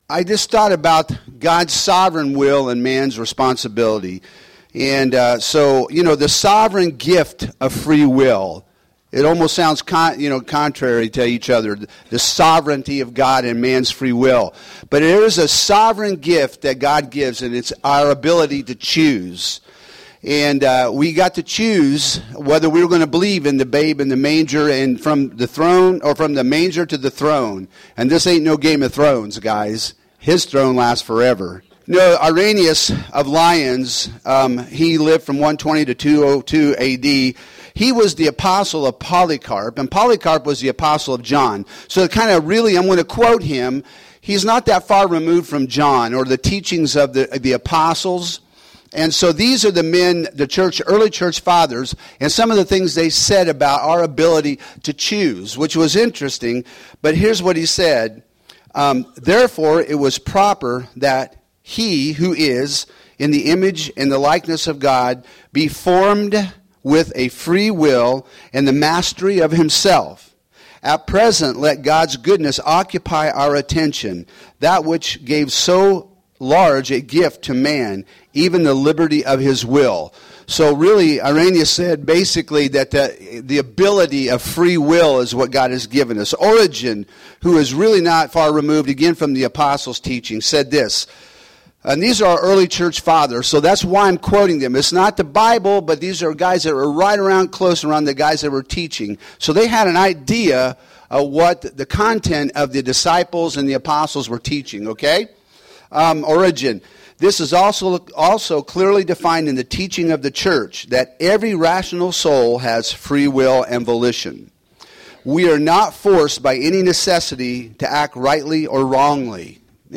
Home › Sermons › Receive or Reject God’s Gift